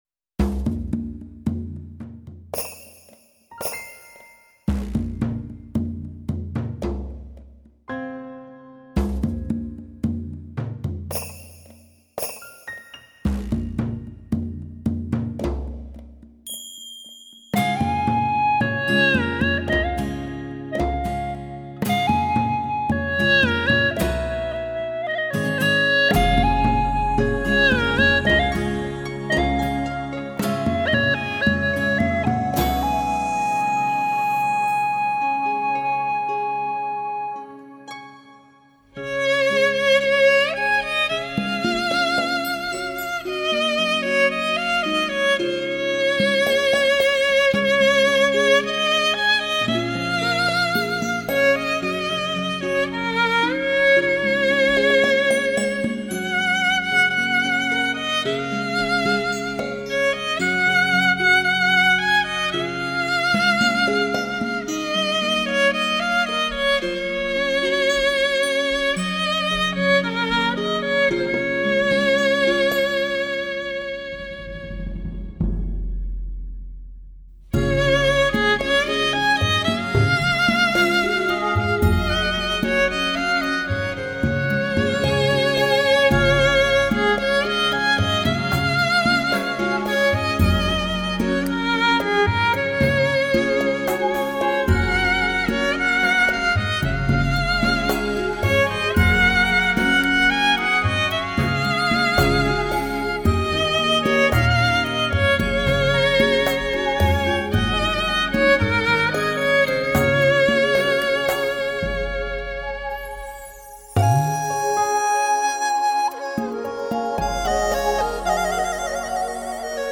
小提琴温暖如梦的音色与古筝、二胡、琵琶、笛子等蕴涵独特民族色彩的乐器交相辉映。
其极致无暇的配器，充满创意的音乐编写，
让小提琴的弓弦交织出飘渺如诗的旋律、